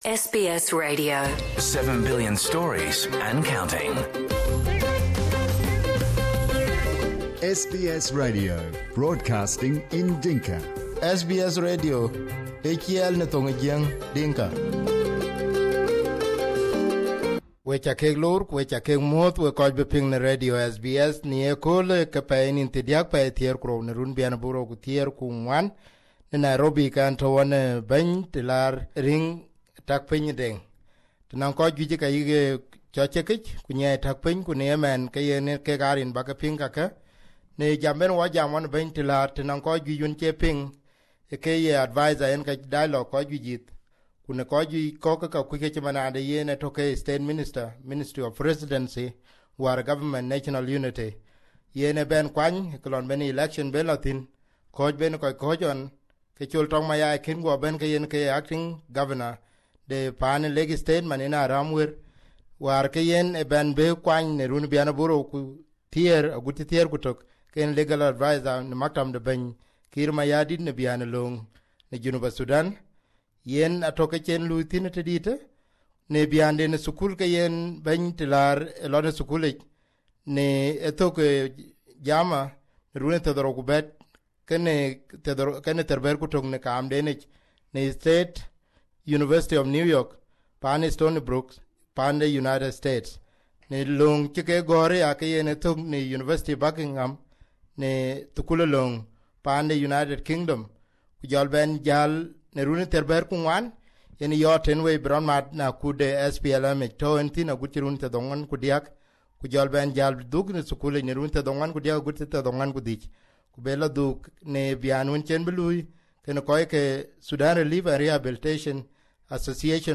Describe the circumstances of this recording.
This is an interview with was recorded on 30/12/2014 in Nairobi and published on the 04/01/2015.